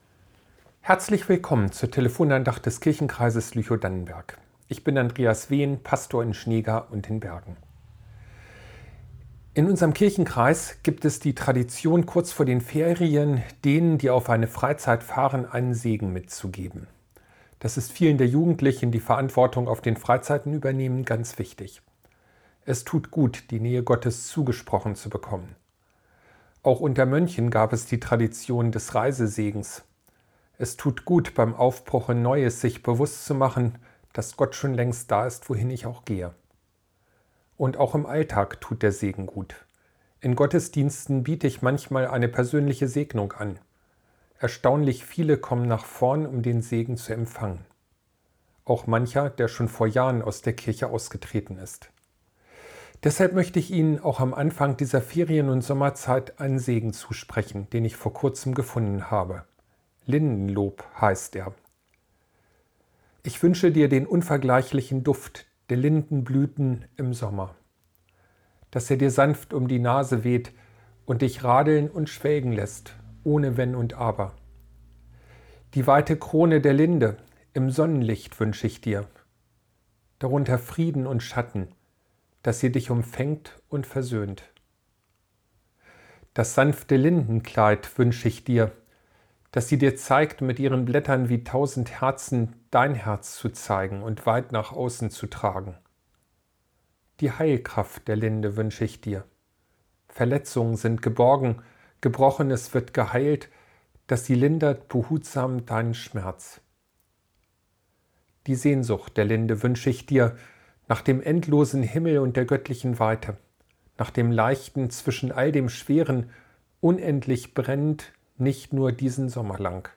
Lindensegen ~ Telefon-Andachten des ev.-luth. Kirchenkreises Lüchow-Dannenberg Podcast
Telefon-Andacht